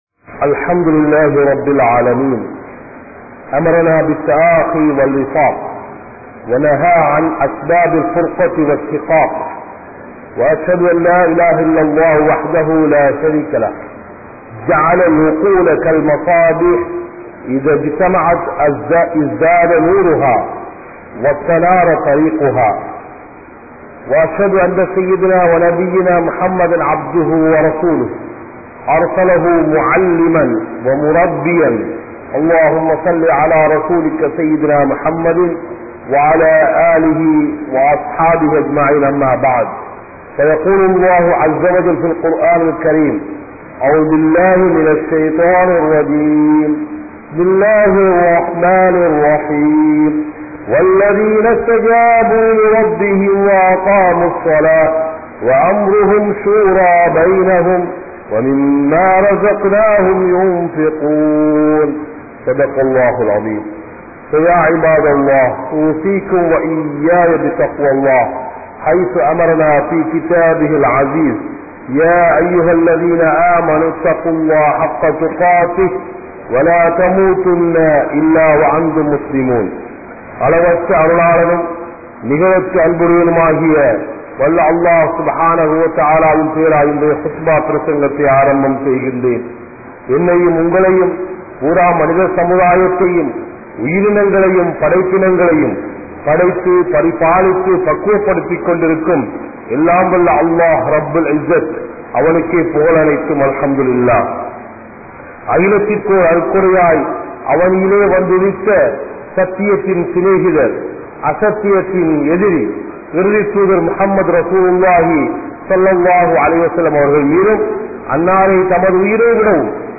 Mashoora`vin Avasiyam (மசூராவின் அவசியம்) | Audio Bayans | All Ceylon Muslim Youth Community | Addalaichenai